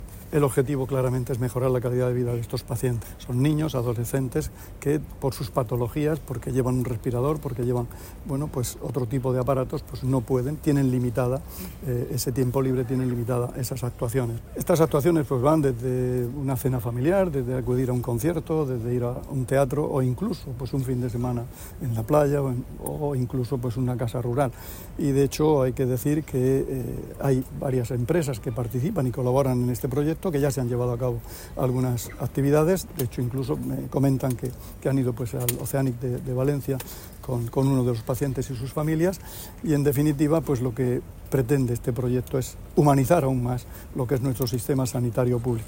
Declaraciones del consejero de Salud durante la presentación del proyecto de ocio terapéutico para menores